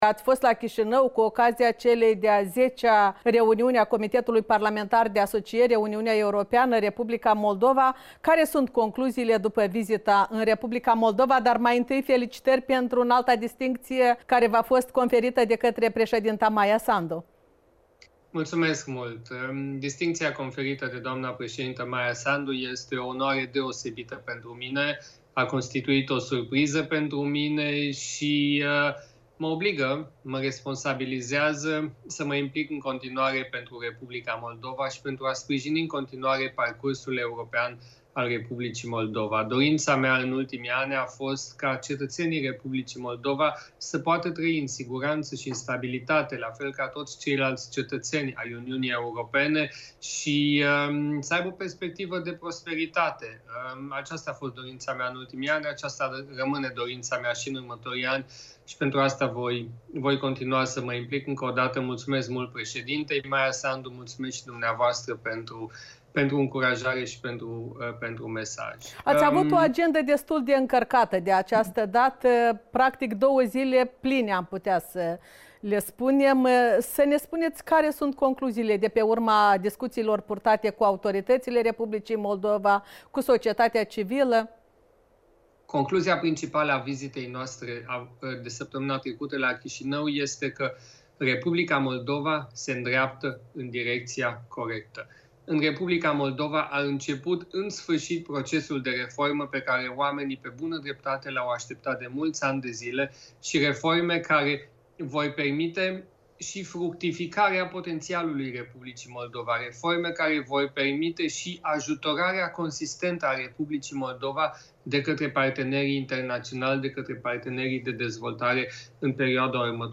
Interviu cu europarlamentarul Siegfried Mureșan